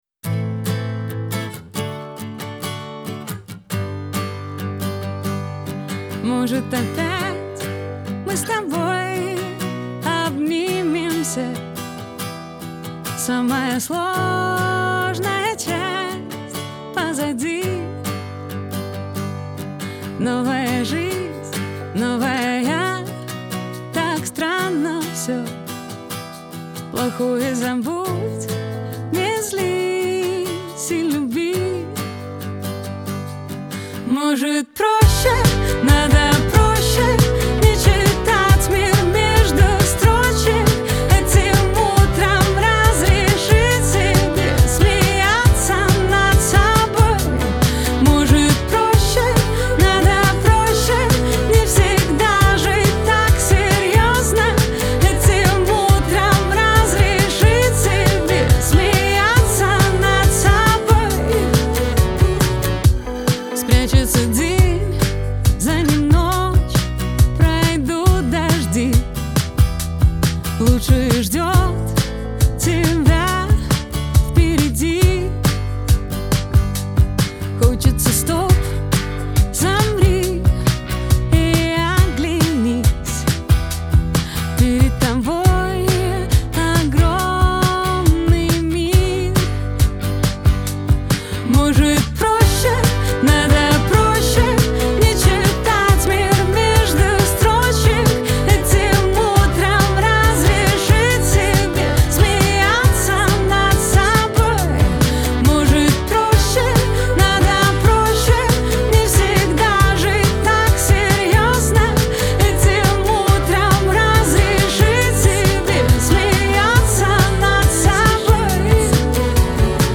pop , диско , Веселая музыка , эстрада